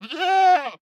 Minecraft Version Minecraft Version snapshot Latest Release | Latest Snapshot snapshot / assets / minecraft / sounds / mob / goat / idle1.ogg Compare With Compare With Latest Release | Latest Snapshot